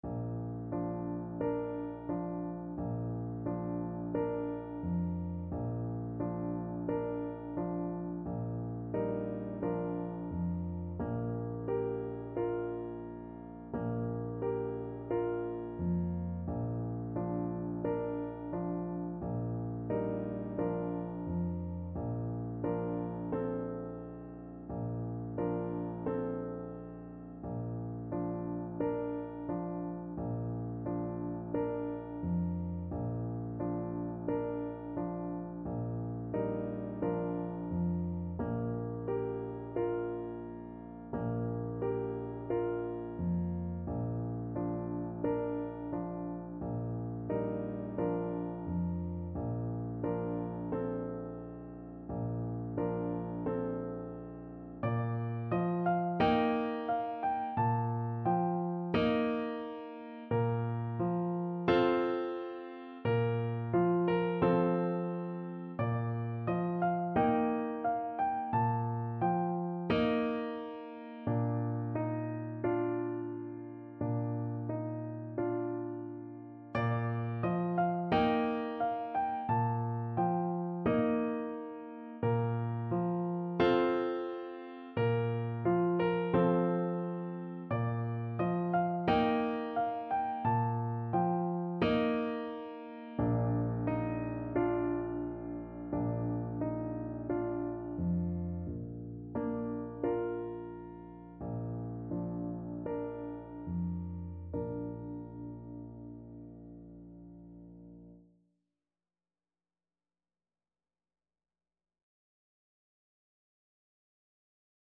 Bassoon
Traditional Music of unknown author.
2/4 (View more 2/4 Music)
Slow
Bb major (Sounding Pitch) (View more Bb major Music for Bassoon )
Irish
irish_lullaby_BN_kar3.mp3